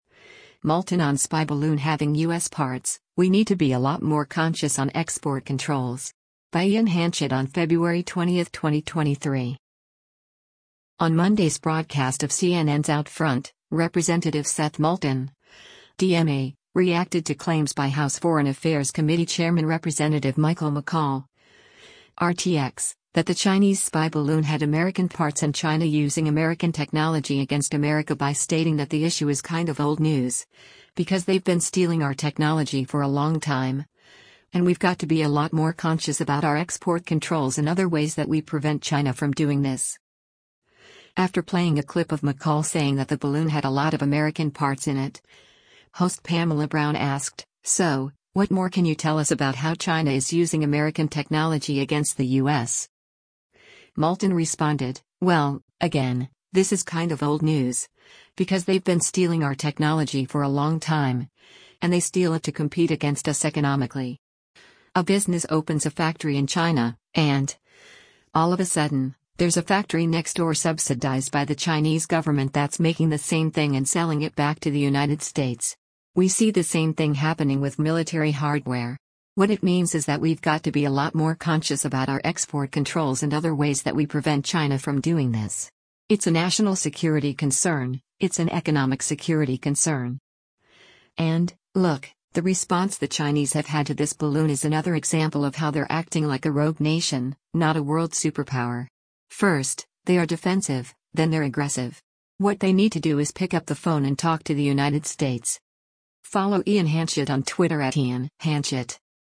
After playing a clip of McCaul saying that the balloon “had a lot of American parts in it,” host Pamela Brown asked, “So, what more can you tell us about how China is using American technology against the U.S.?”